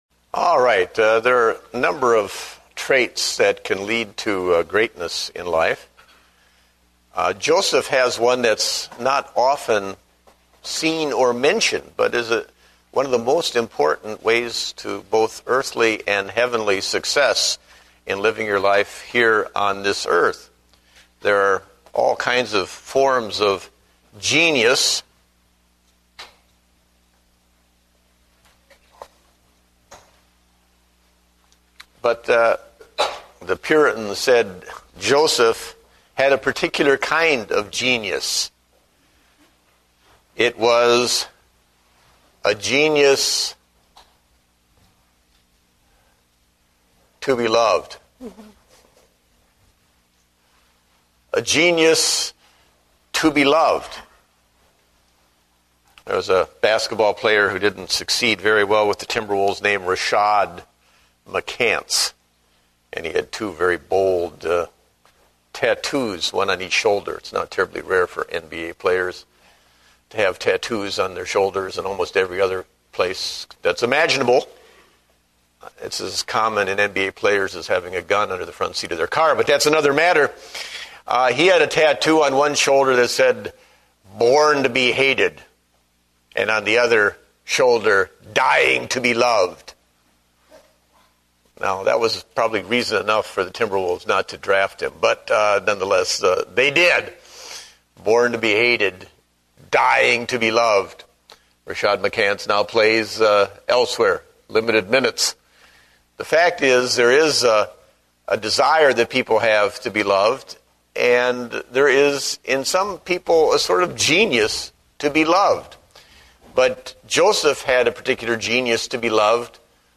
Date: May 3, 2009 (Adult Sunday School)